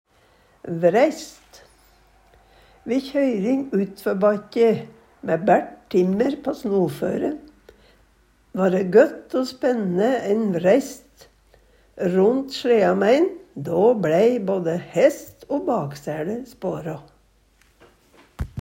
DIALEKTORD
vræist-lækkje vræisten- lekkja vræista- lekkja vræistan- lekkjun